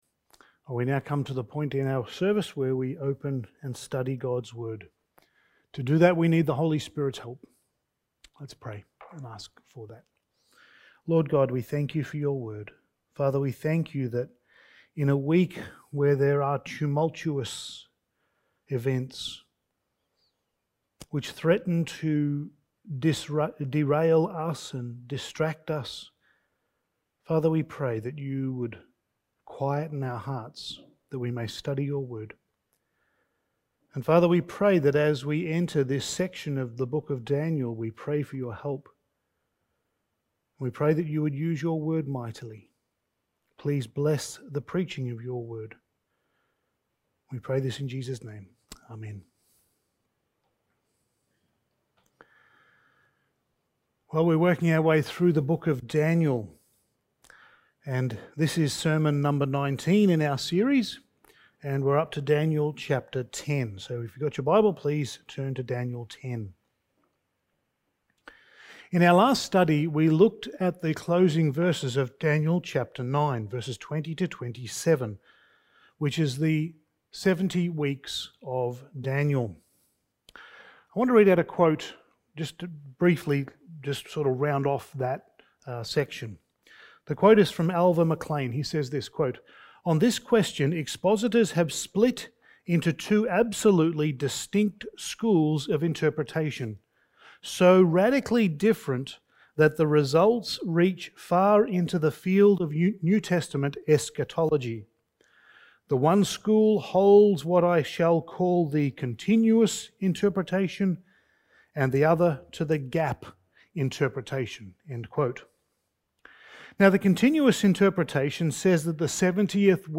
Passage: Daniel 10:1-21 Service Type: Sunday Morning